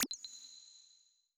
generic-hover-soft.wav